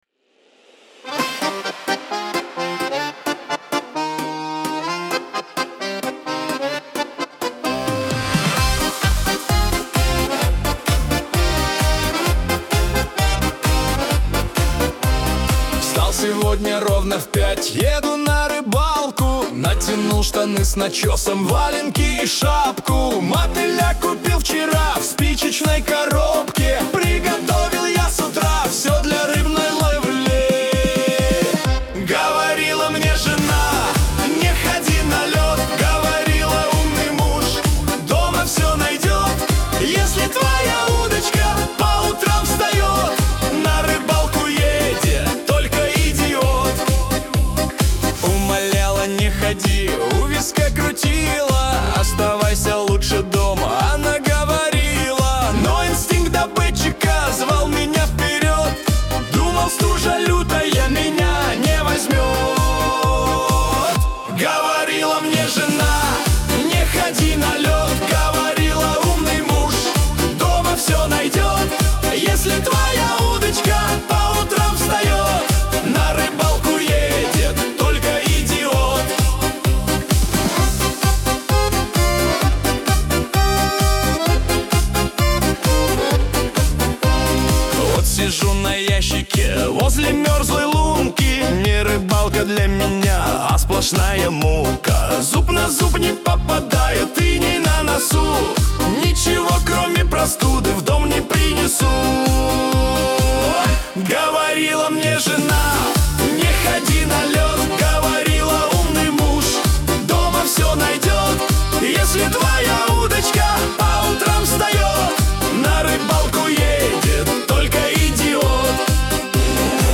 Шансон
Веселая музыка
диско